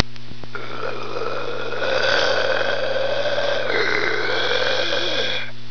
* Pour l’audition exclusive et sensationnelle de la voix de M Chevalier dans « Bottom in the night », cliquez ici !
ultrasons2.wav